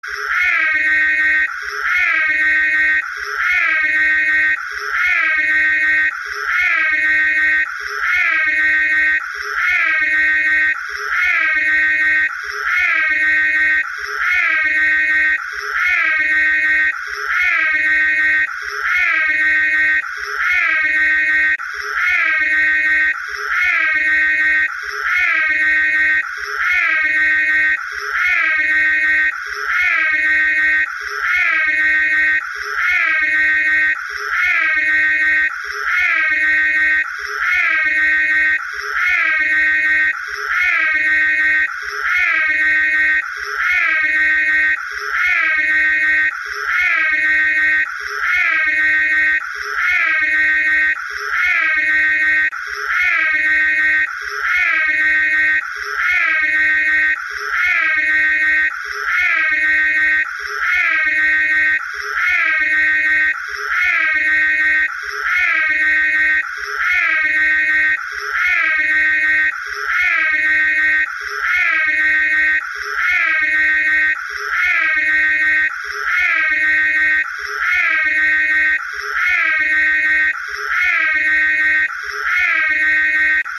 Звук сирены Ecto-1 из Охотников за привидениями